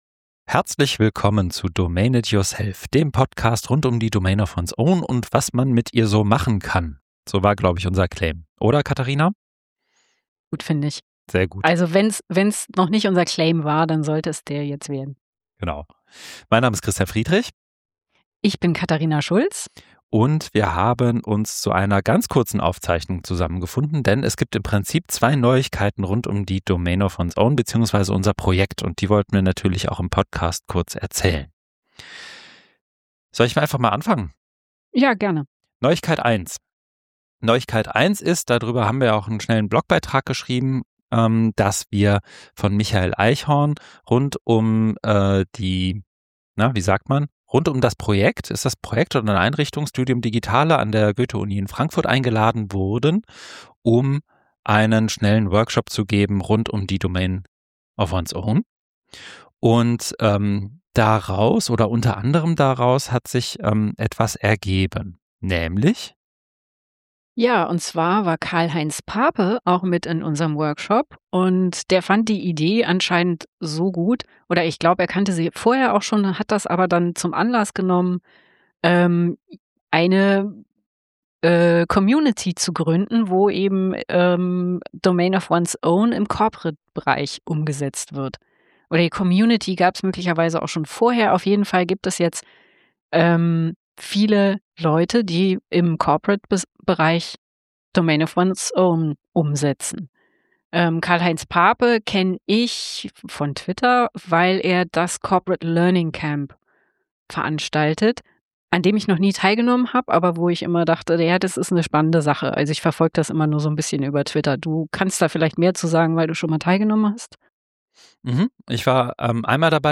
Gespräch im Rahmen der Konferenz OERxDomains21